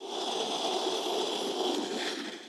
SFX_Door_Slide_04.wav